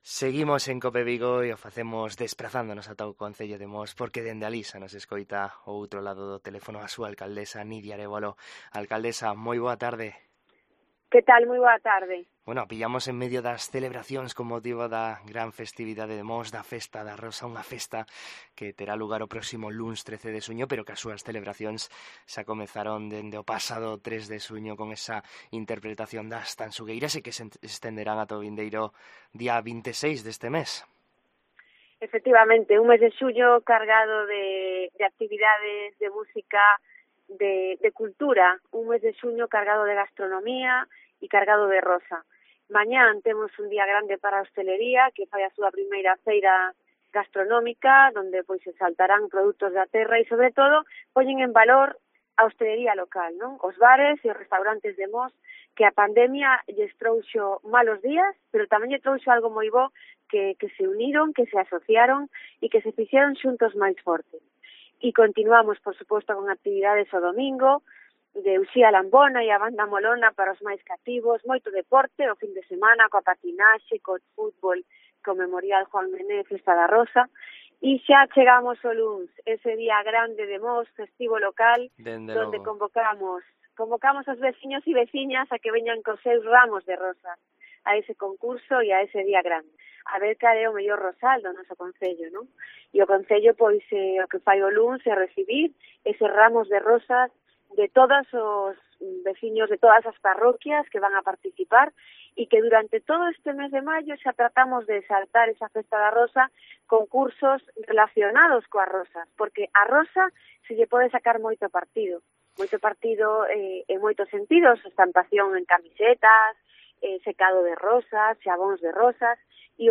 AUDIO: En COPE Vigo coñecemos a actualidade de Mos da man da súa alcaldesa, Nídia Arévalo